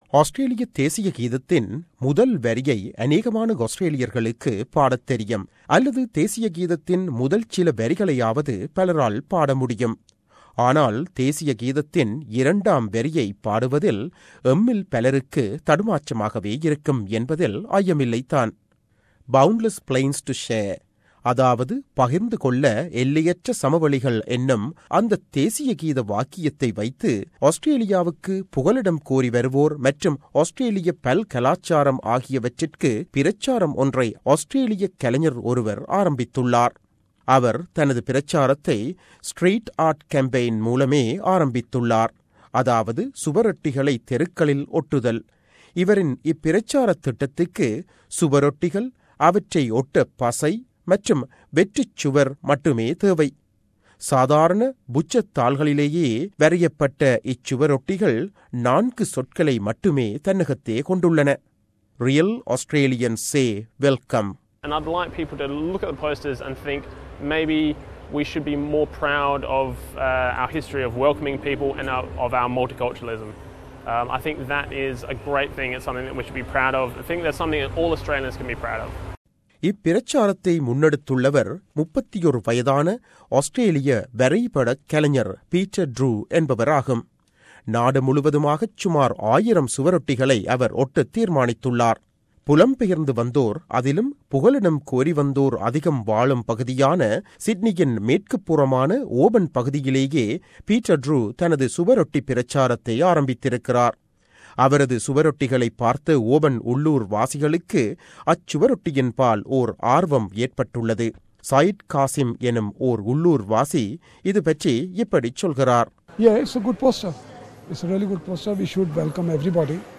செய்திவிவர்ணத்தைத் தமிழில் தருகிறார்